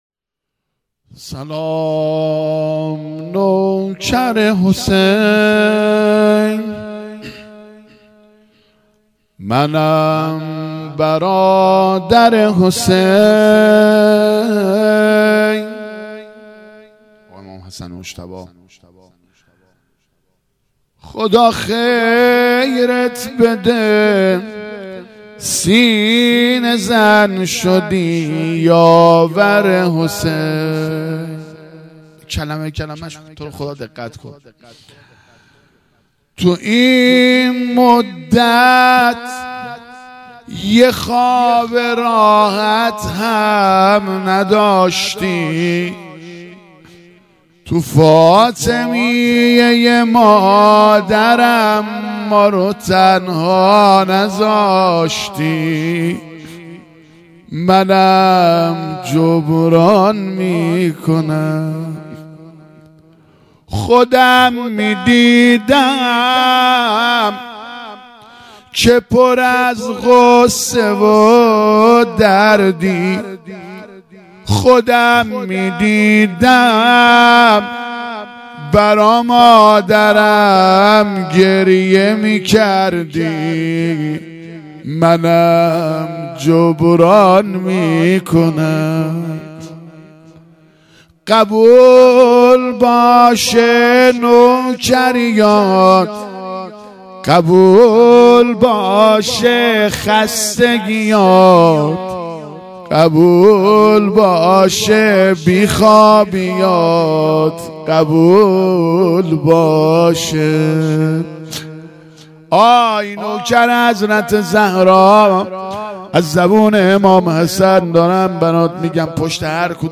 هیئت مکتب الزهرا(س)دارالعباده یزد - روضه | سلام نوکر حسین مداح